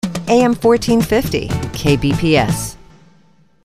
They have in the past, graciously donated their services to provide KBPS with on-air imaging.
Randy is probably best know as the voice of Entertainment Tonight & The Insider.